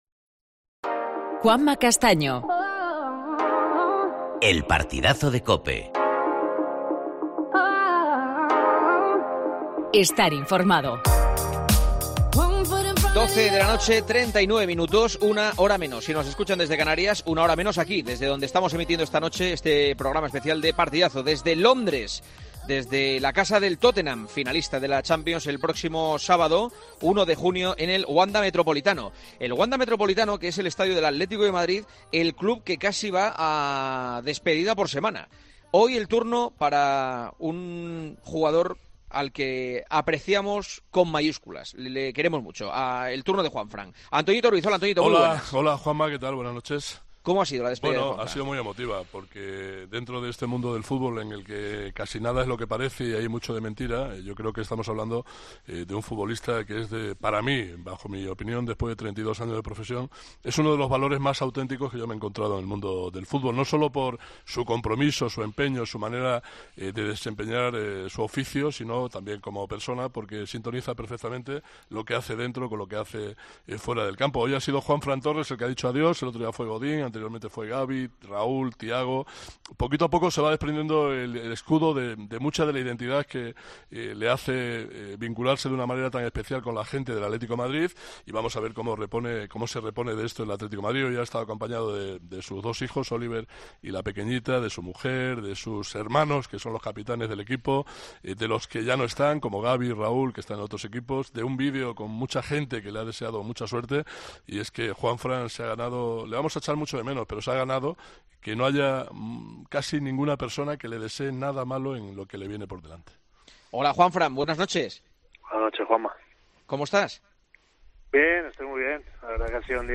Horas después, en su entrevista en "El partidazo de COPE", Juanfran ha asegurado que esta decisión la llevaba meditando desde hacía varios meses.